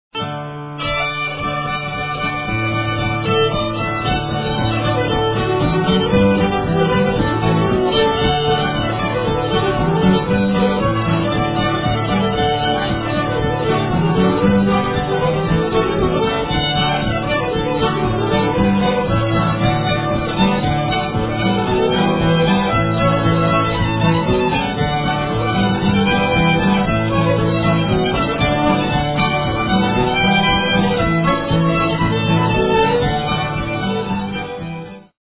Vermont old-time music
in homes and at community events